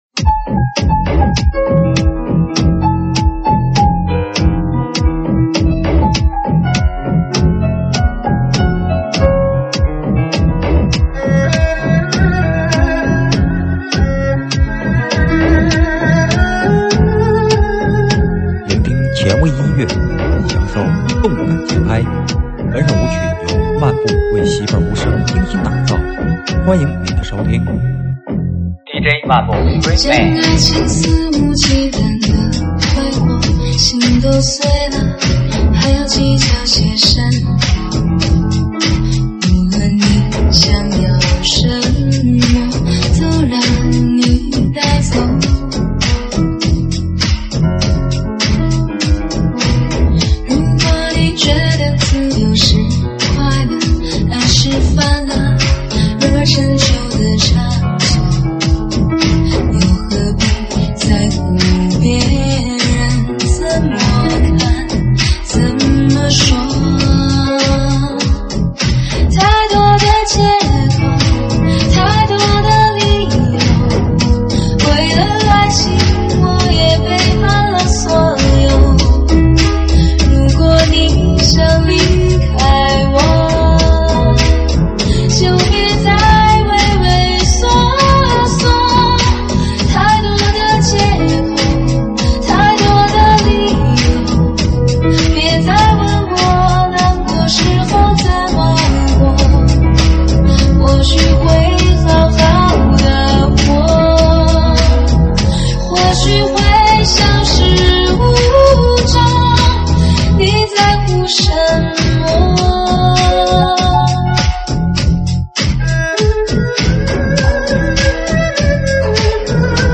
栏目：慢摇舞曲